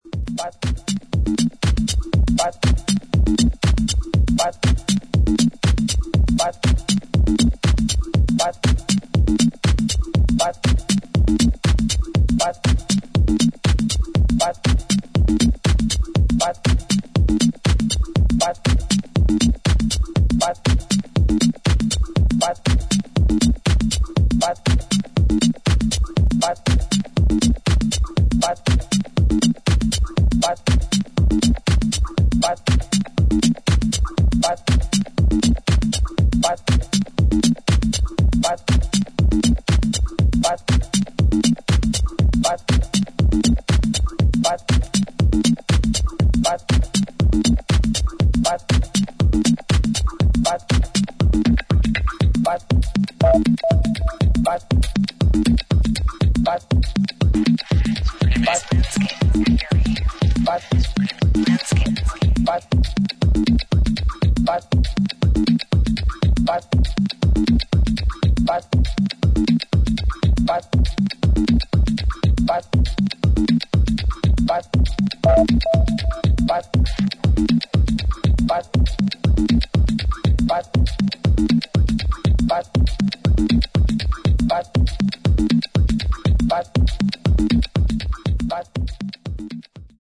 ブレずに積み重ねてきたスタイルと、最小限の音で表現する独特のファンクネスが堪能できる一枚です。